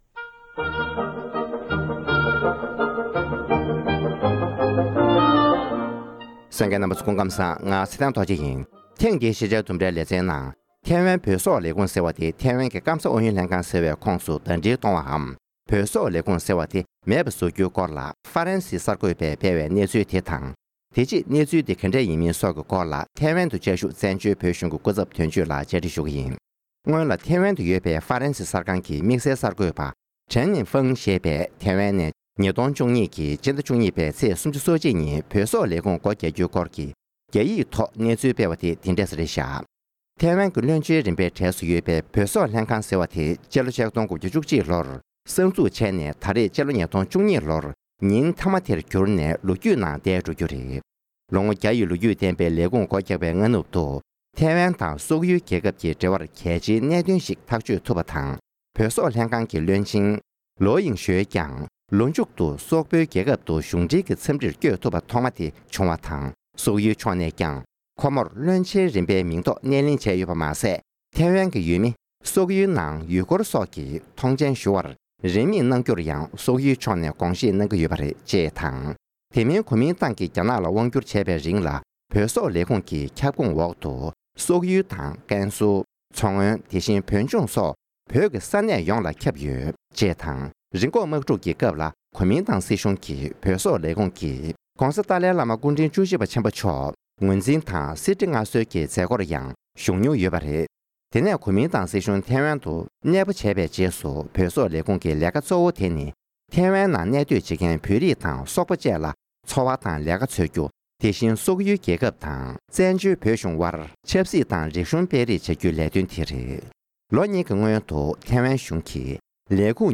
ཞལ་པར་རྒྱུད་བཅར་འདྲི་ཞུས་པ་ཞིག་ལ་གསན་རོགས་ཞུ༎